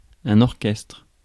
Ääntäminen
Ääntäminen France: IPA: [oʁ.kɛstʁ] Tuntematon aksentti: IPA: /ɔʁ.kɛstʁ/ Haettu sana löytyi näillä lähdekielillä: ranska Käännös Konteksti Ääninäyte Substantiivit 1. orchestra musiikki UK US 2. stall Suku: m .